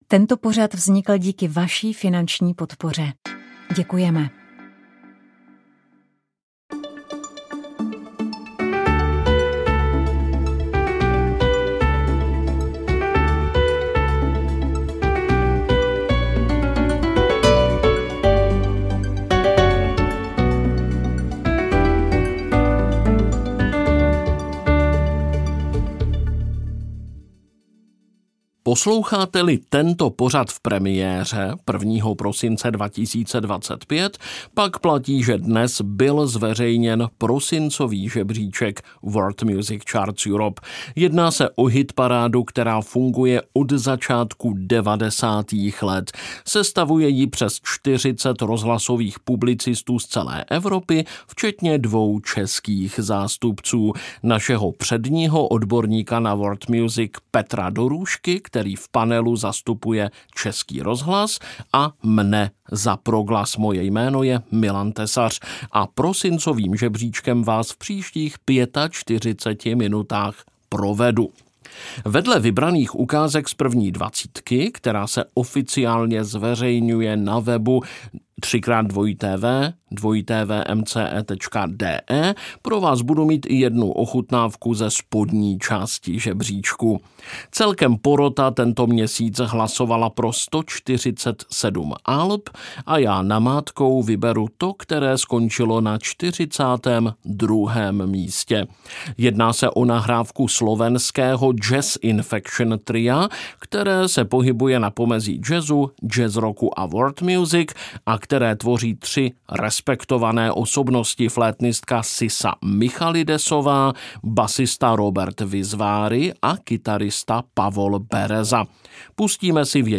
jihokorejská jazzová skupina
živě z Koncertního studia